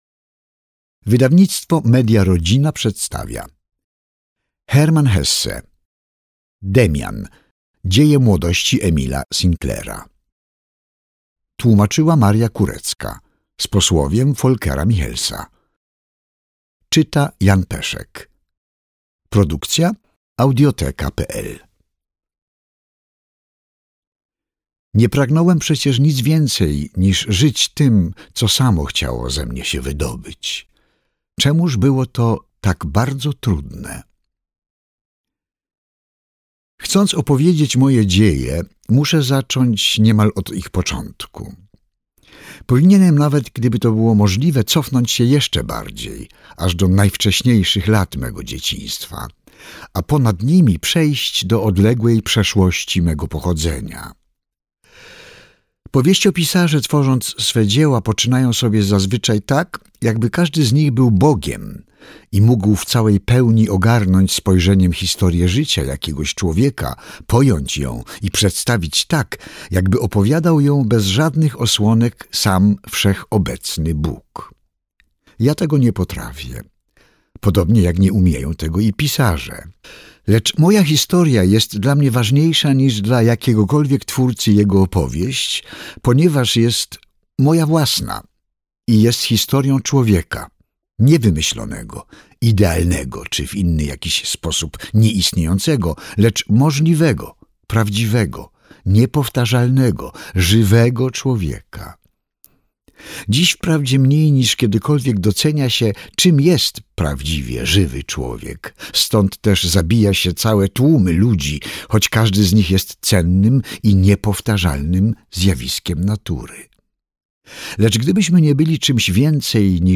Demian - Hermann Hesse - audiobook + książka